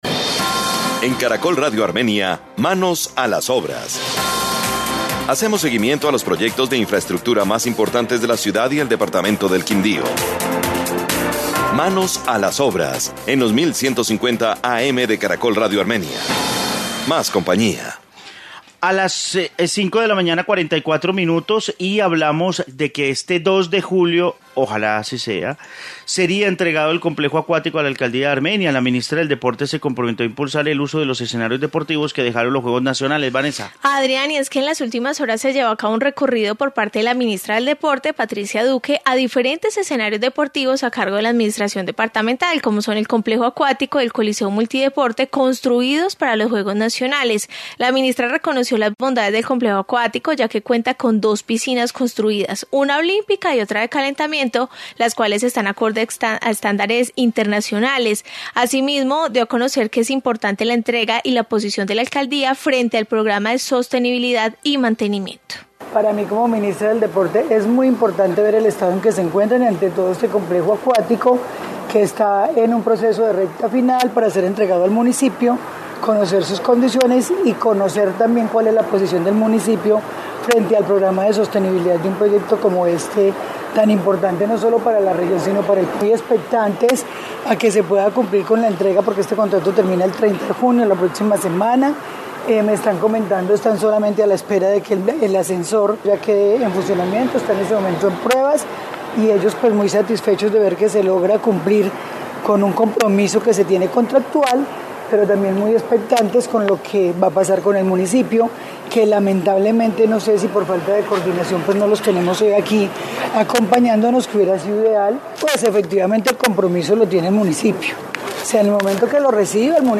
Informe visita ministra del Deporte